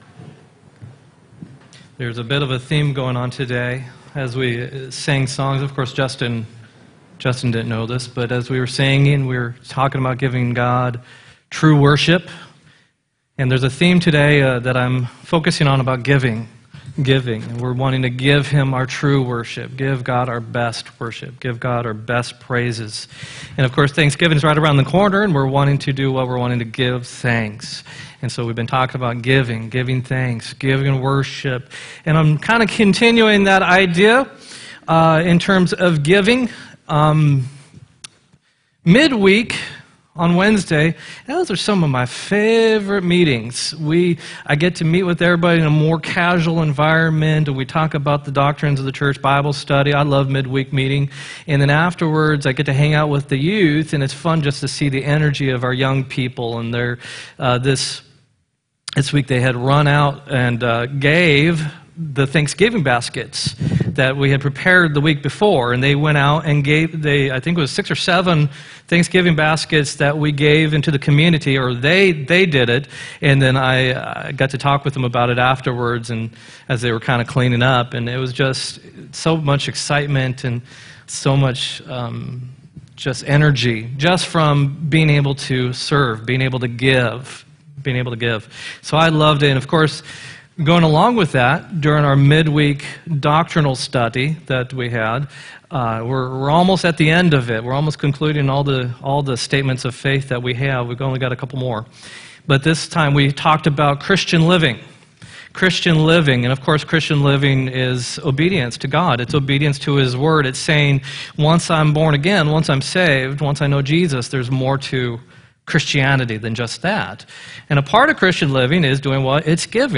11-18-17 sermon